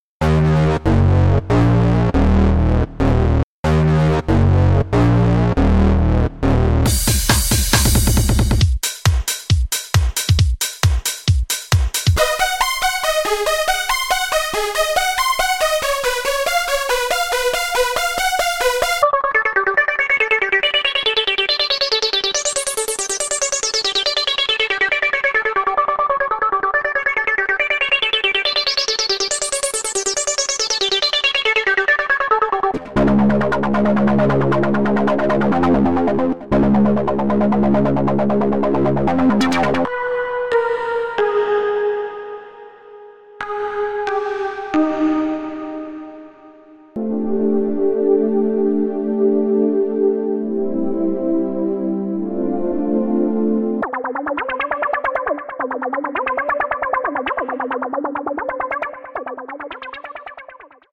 These Trance loops will have you pumping out floor shaking club bangers in minutes.
Each song kit gives you the basics such as drum loops, bass and lead lines as well some arpeggios, strings, pads, keyboards, chimes, retro synth’s and much more.
trance_pack1.mp3